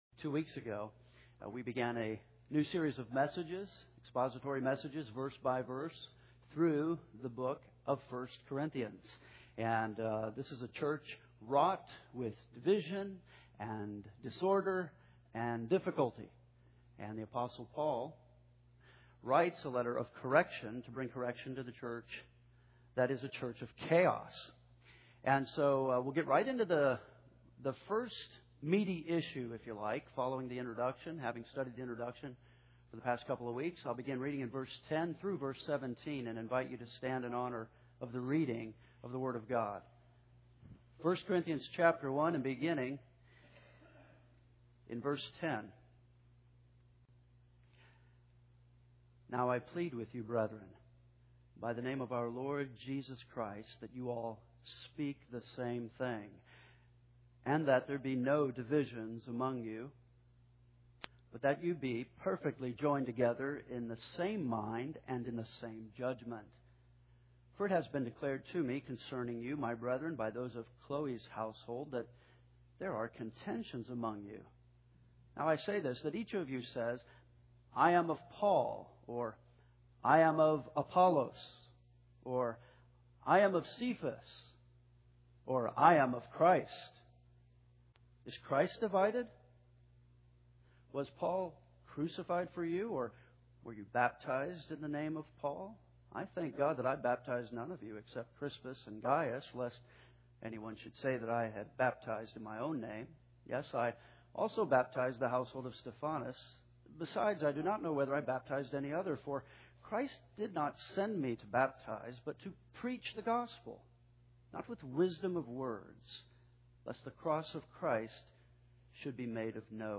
Two weeks ago we began a new series of expository messages, verse-by-verse, through the book of 1 Corinthians.